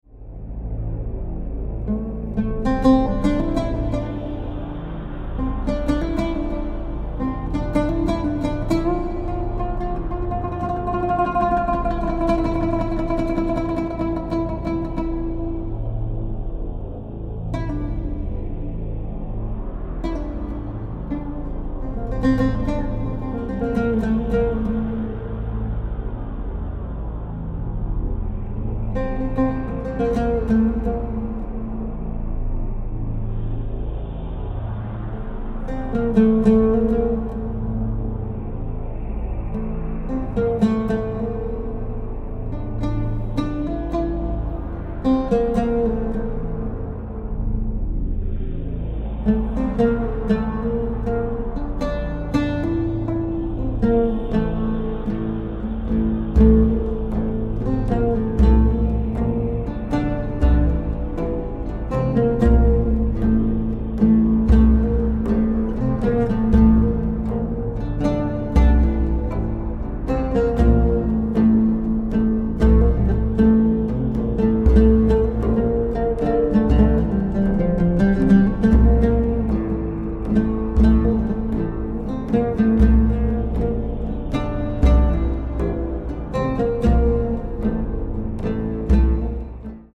Contemporary
Oriental , Oud